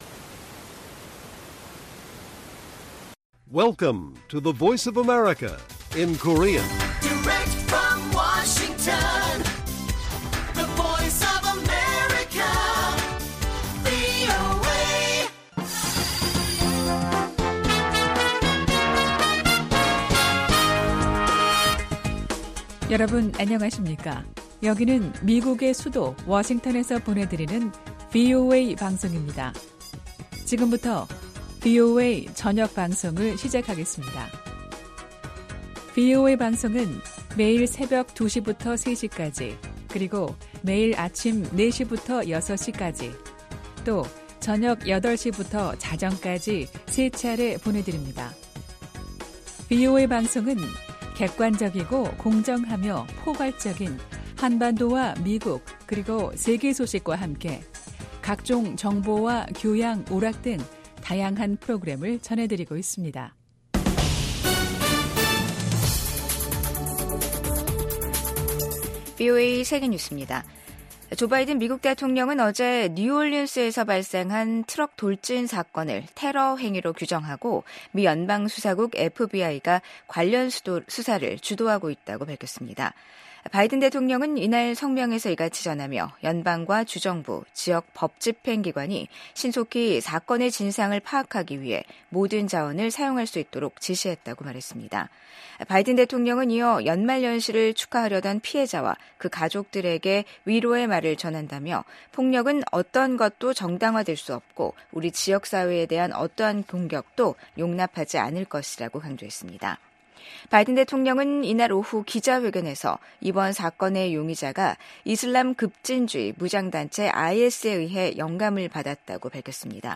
VOA 한국어 간판 뉴스 프로그램 '뉴스 투데이', 2025년 1월 2일 1부 방송입니다. 주한 미국 대사와 전현직 주한미군 사령관들이 신년사에서 미한동맹의 굳건함을 강조하며 동아시아 안보 강화를 위한 협력을 다짐했습니다. 전 세계에서 중국과 치열한 경쟁을 벌이고 있는 미국은 ‘미국 우선주의’를 내세운 도널드 트럼프 2기 행정부가 출범하면 한국에 중국 견제를 위한 더 많은 역할을 주문할 것으로 예상됩니다.